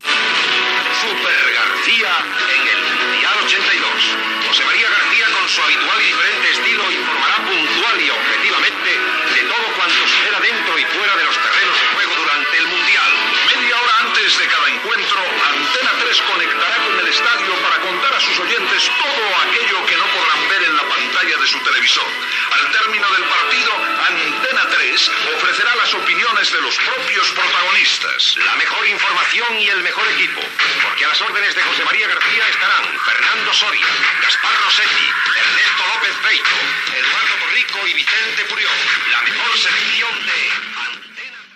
Promoció de la progrmació especial per seguir el mundial de futbol masculí Espanya 1982